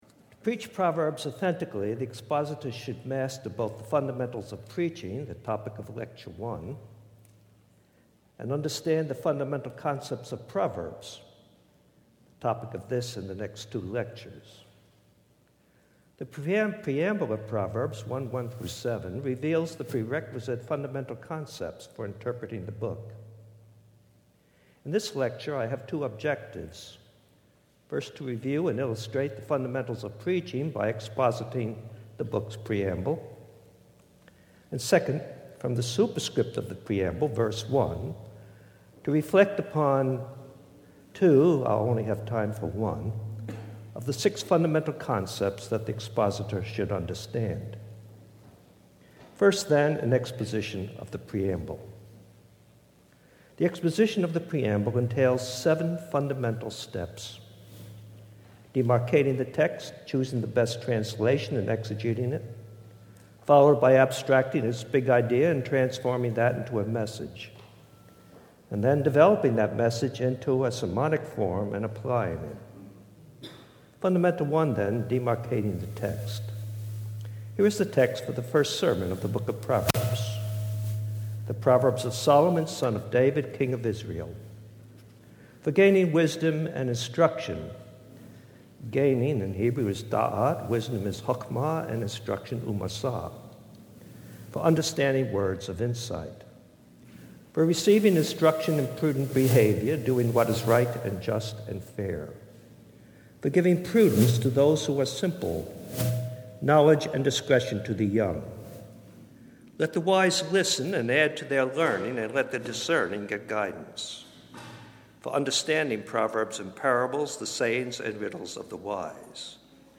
Introduction to the Lecture Series